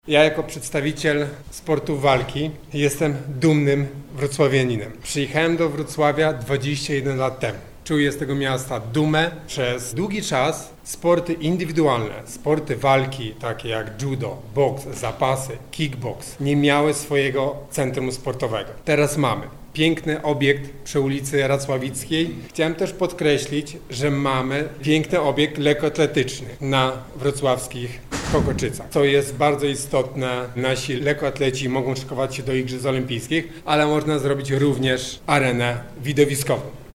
– Spotkaliśmy się po to, by wyrazić słowa wsparcia, wdzięczności i podziękowania. – mówi Mateusz Masternak, zawodowy pięściarz, mistrz Europy federacji EBU w 2012 roku.